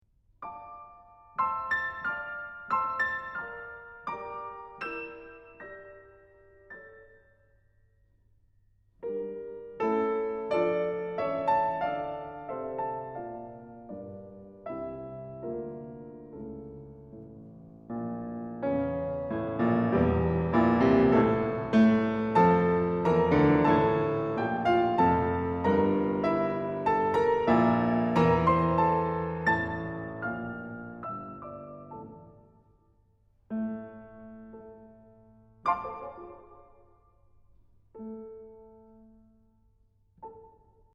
And if you're REALLY ambitious, here's the piece in its entirety, performed on the piano (pianist: Olli Mustonen):